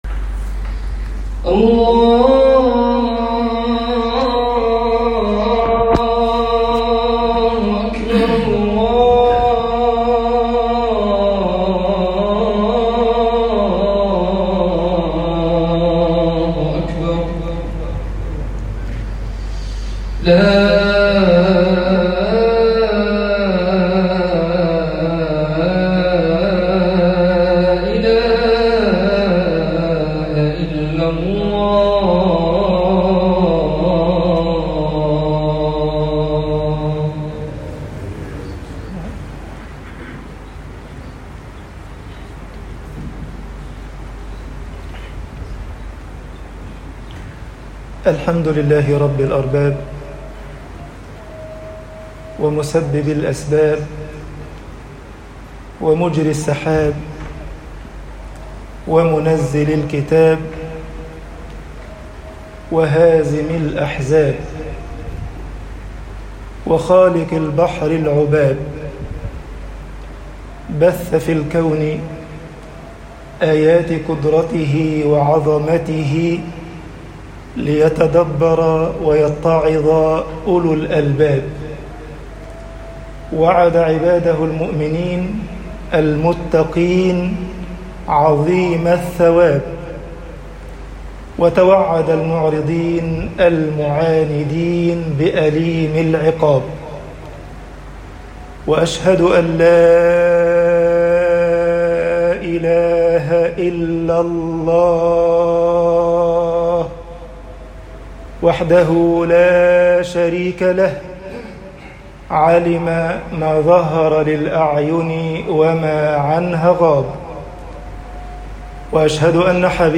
خطب الجمعة - مصر فنادى في الظّلمات طباعة البريد الإلكتروني التفاصيل كتب بواسطة